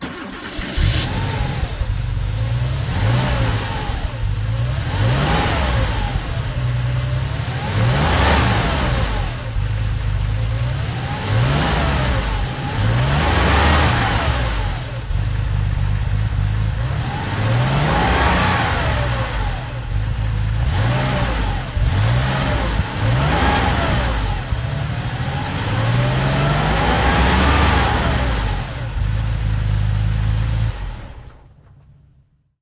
保时捷降速音效
描述：保时捷降速音效
标签： 汽车 保时捷 降速
声道立体声